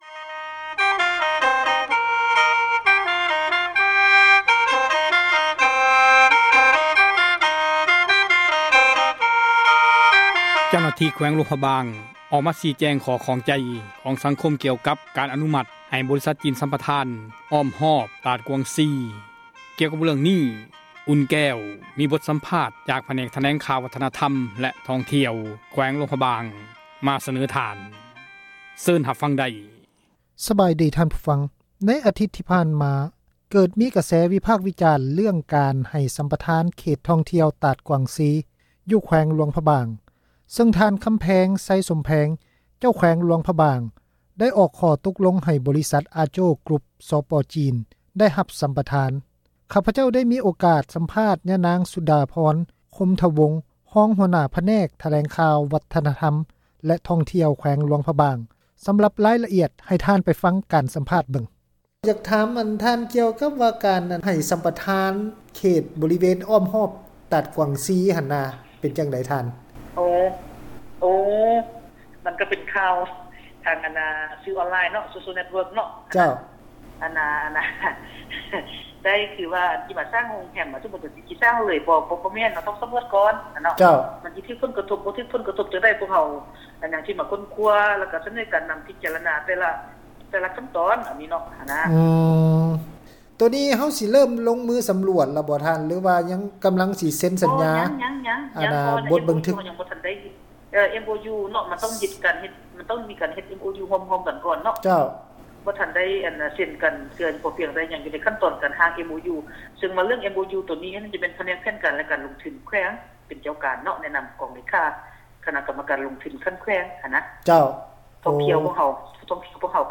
ຂ້າພະເຈົ້າ ໄດ້ມີ ໂອກາດ ສໍາພາດ